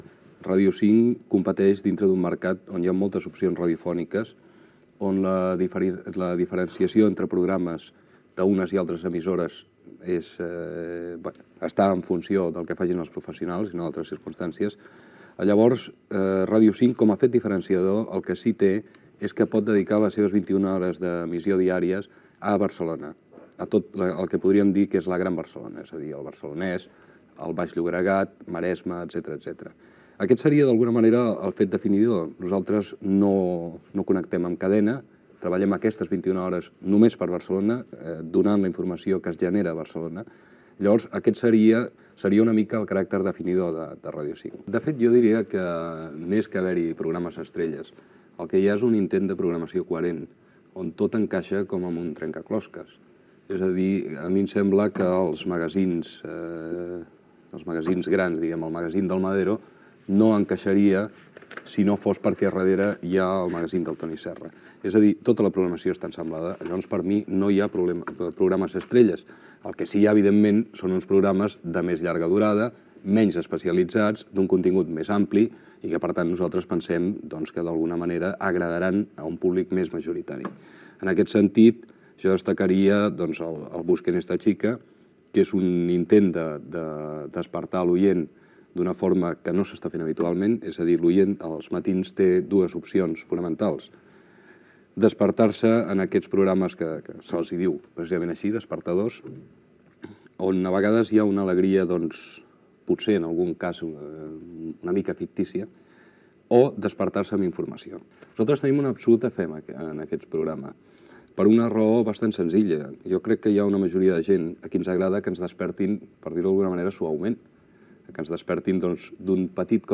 Extret del programa de TVE a Catalunya "Temes d'avui" emès el 4 d'abril del 1983